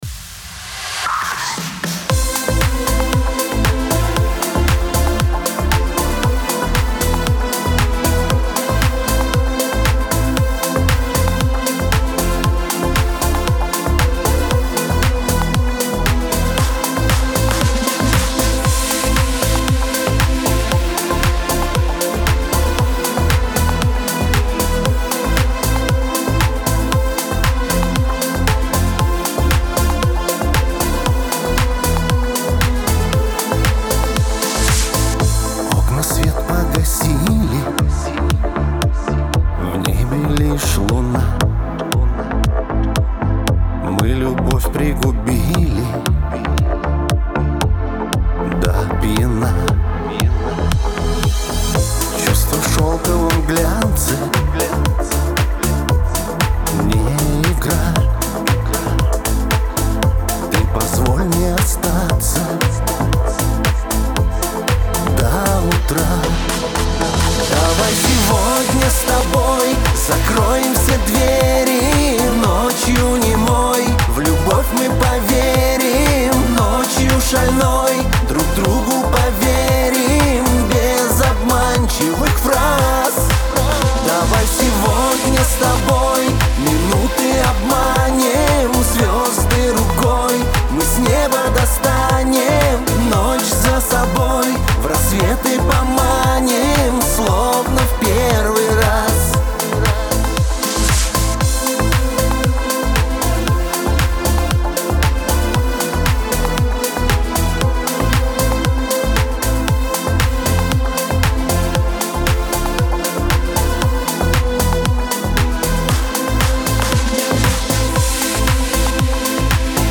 Скачать музыку / Музон / Песни Шансона